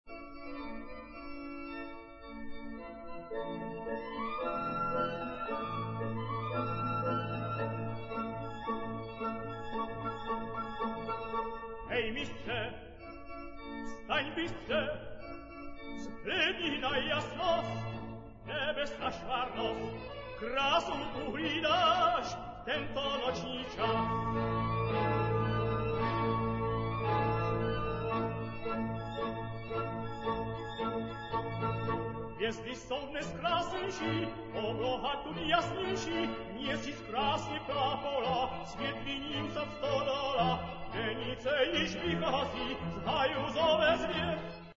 Česká mše vánoční (Hej, mistře!) (MP3) (1. Kyrie; Beno Blachut - zpěv, Milan Šlechta - varhany, LP Supraphon 1967)